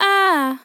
TEN VOCAL FILL 30 Sample
Categories: Vocals Tags: dry, english, female, fill, sample, TEN VOCAL FILL, Tension